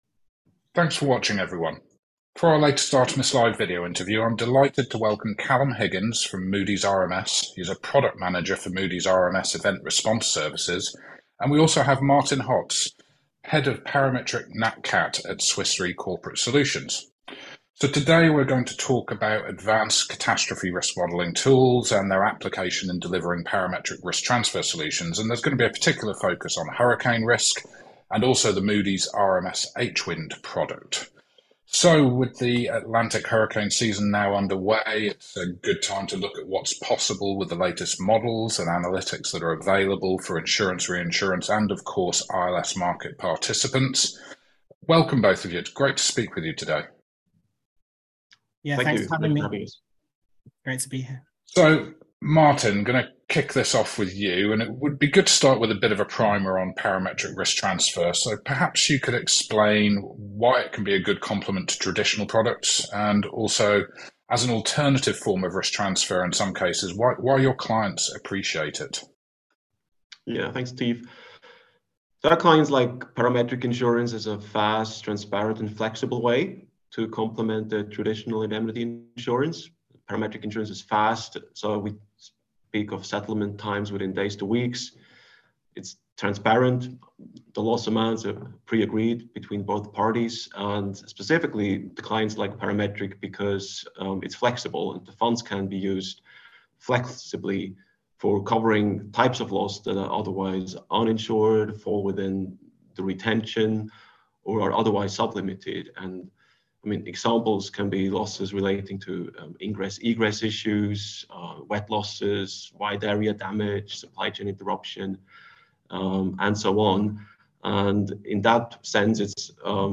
Parametric hurricane cover powered by HWind: Moody's RMS & Swiss Re Corporate Solutions interview